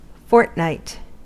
Ääntäminen
US : IPA : [ˈfɔɹt.ˌnaɪt]